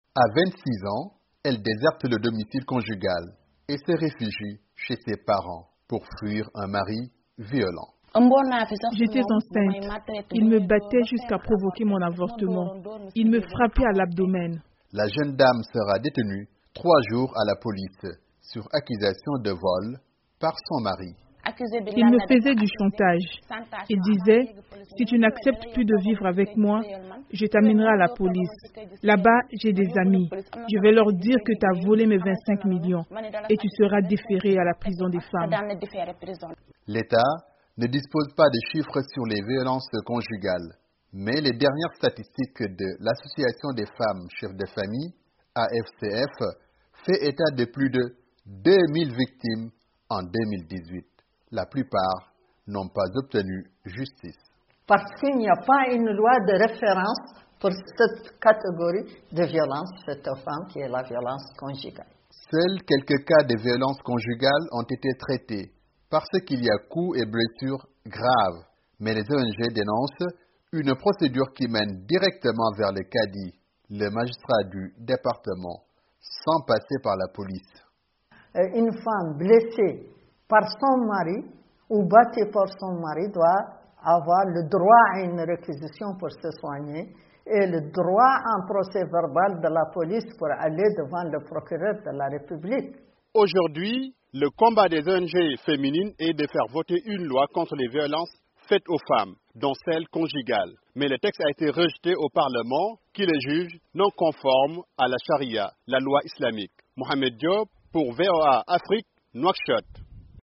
En Mauritanie, le Ministère des Affaires sociales, de l'Enfance et de la Famille, appuyé par la société civile, a lancé une campagne contre les violences conjugales. Difficile de convaincre dans une société conservatrice où un quart des femmes ne condamnent pas ces violences, selon un rapport MICS. Un reportage